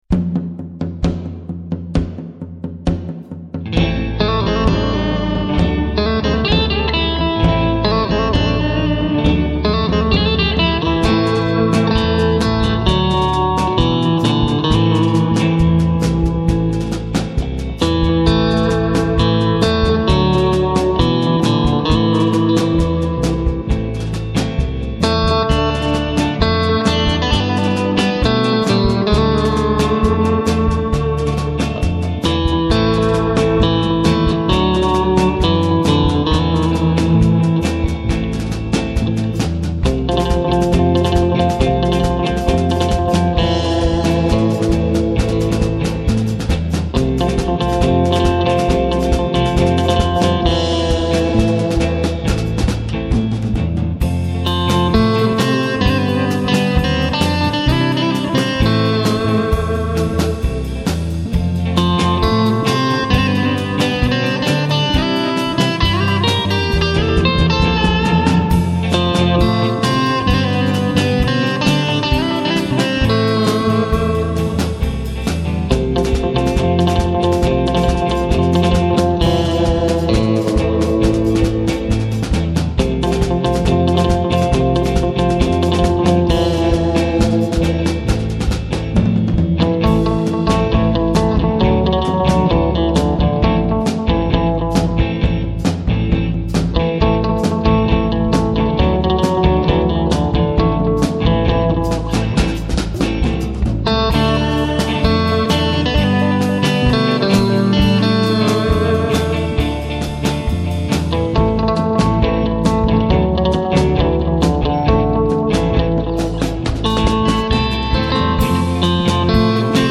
Batterie
Basse
le style: ann�es 60/70